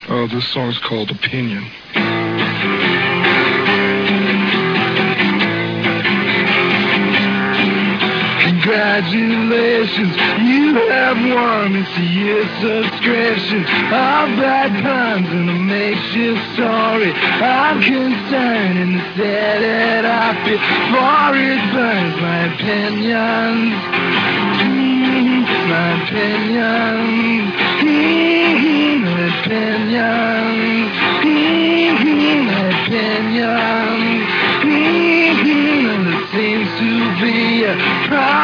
played acoustically solo
with their studios in Olympia, WA.